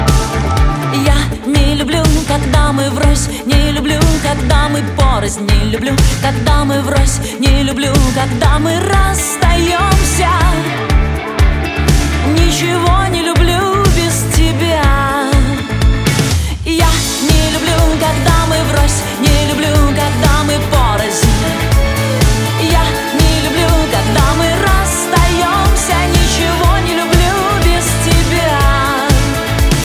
• Качество: 128, Stereo
рок